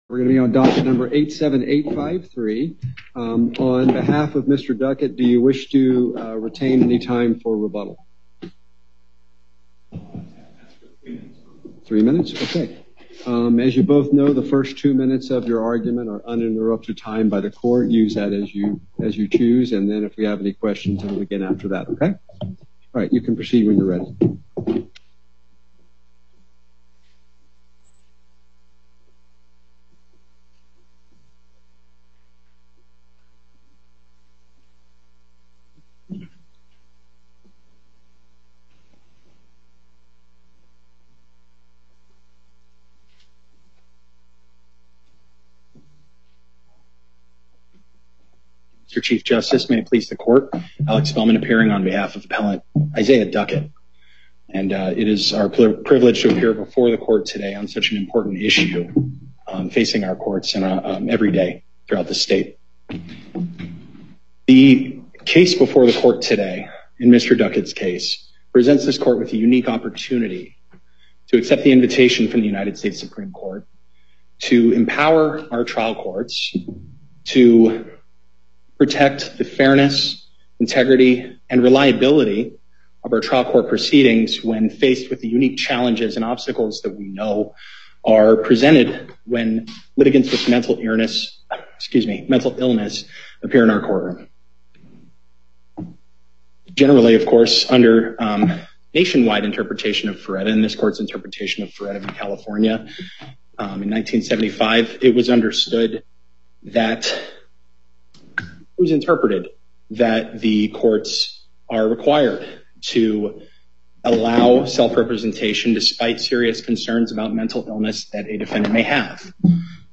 Before the En Banc Court, Chief Justice Herndon presiding Appearances
on behalf of Respondent